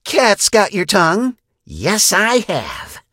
kit_kill_vo_03.ogg